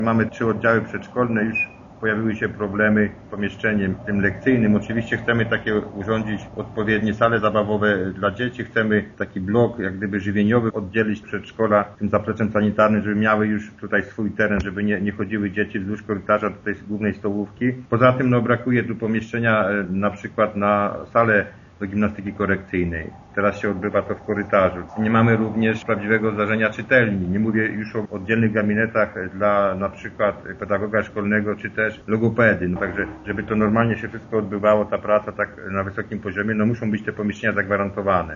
Wójt Jan Filipczak tłumaczy, że powiększenie budynku jest niezbędne: